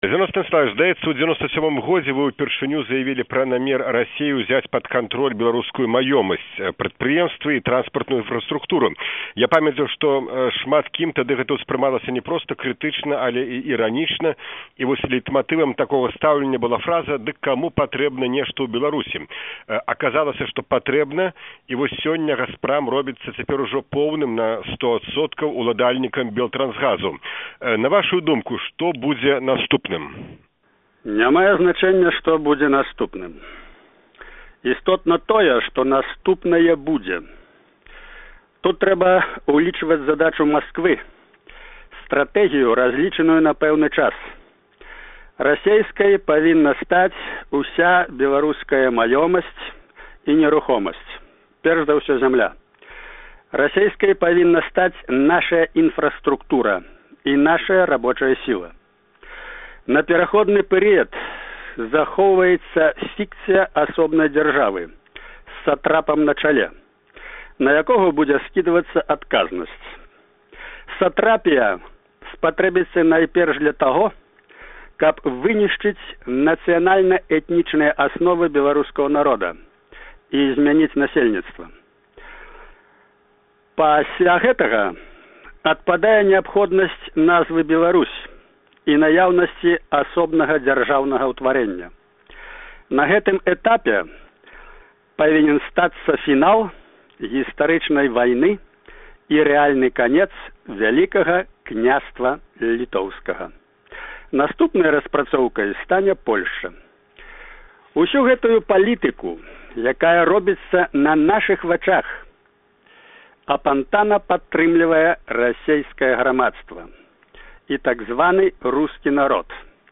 Інтэрвію зь Зянонам Пазьняком